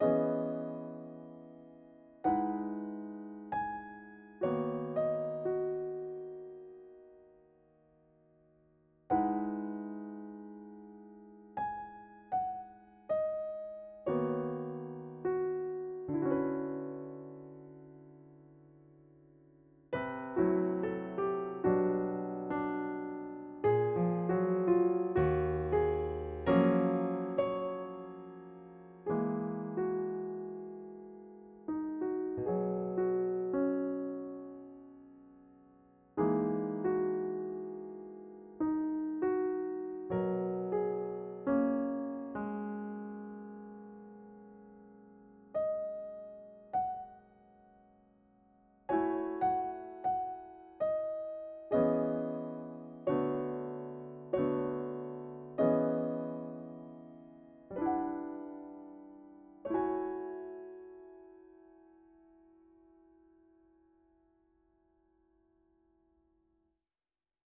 時にシンプルで 時に複雑なひびき 重なりあう音色の変化 ジャジーなテンション 人間らしいアナログな間